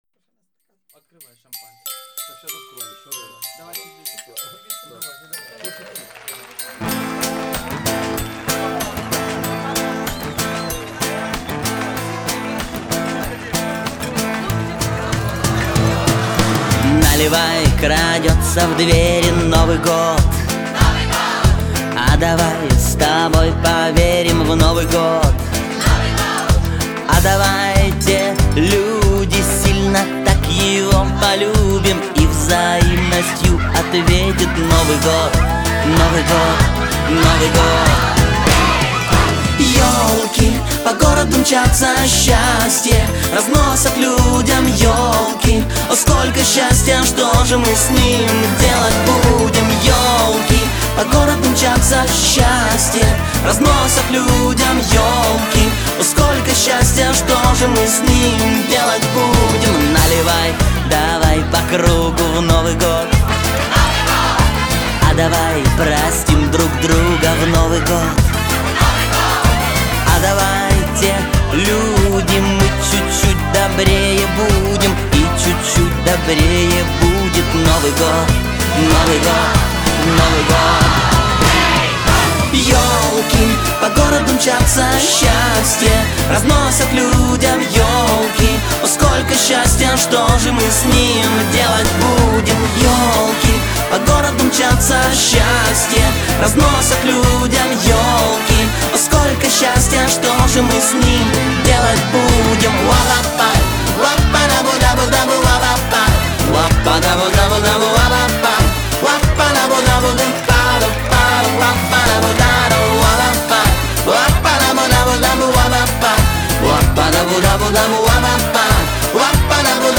Категория : Поп